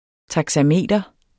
Udtale [ tɑgsaˈmeˀdʌ ]